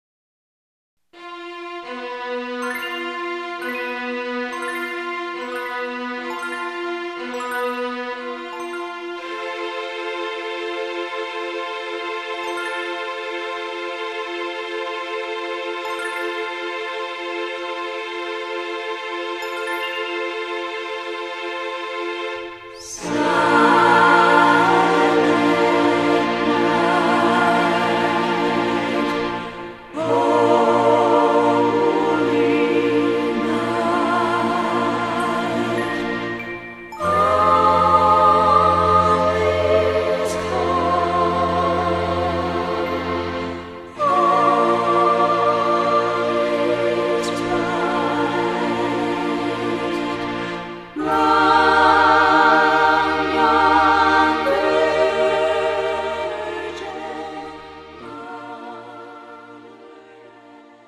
고음질 반주